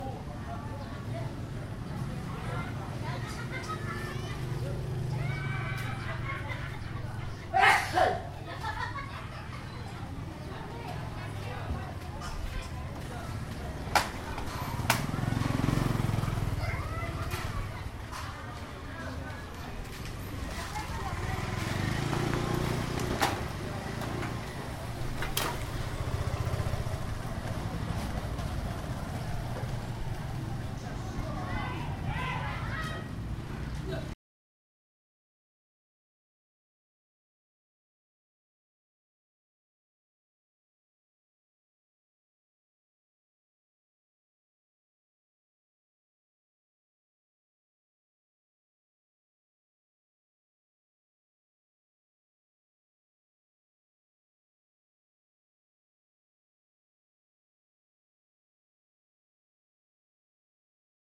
Kids Laughing and a Man is Sneezing in the streets of Cambodia
Category 🤣 Funny
asia authentic cambodia fun funny giggle happiness kids sound effect free sound royalty free Funny